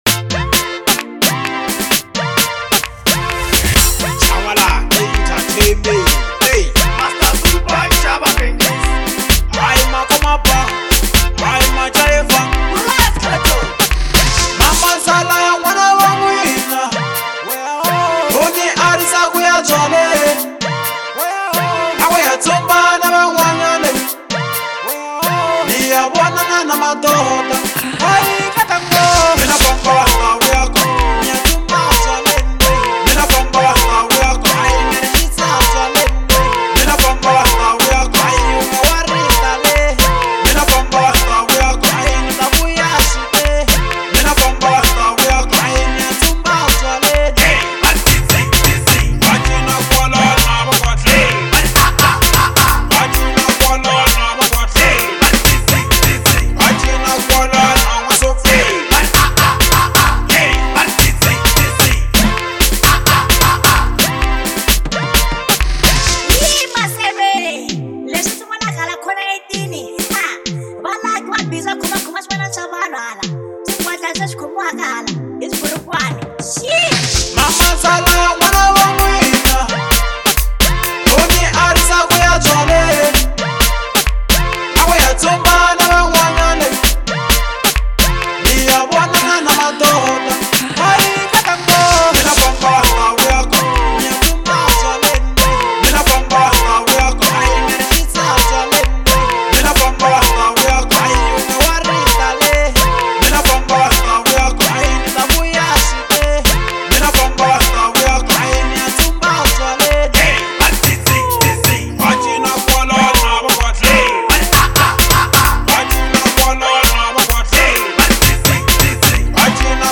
Genre : Local House